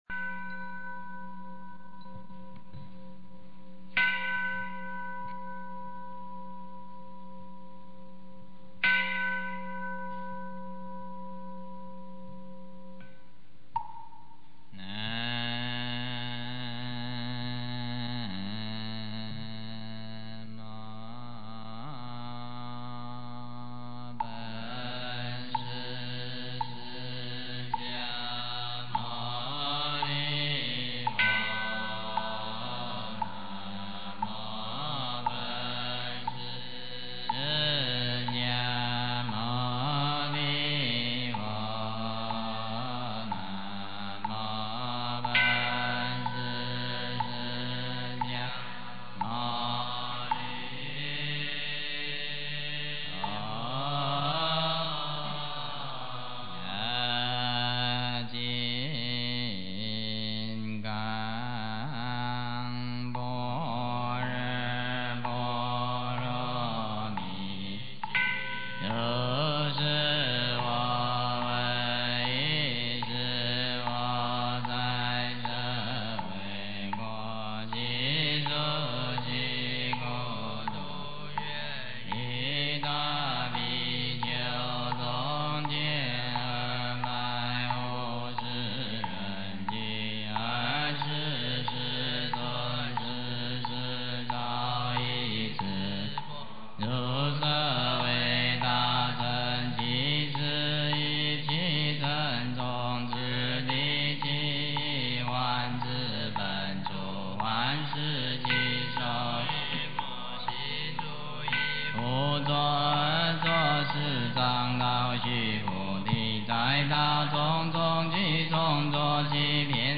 金刚经(唱颂)
诵经 金刚经(唱颂
佛音 诵经 佛教音乐 返回列表 上一篇： 大势至菩萨念佛圆通章 下一篇： 大势至菩萨念佛圆通章（念诵） 相关文章 体悟本性--佛陀精神 体悟本性--佛陀精神...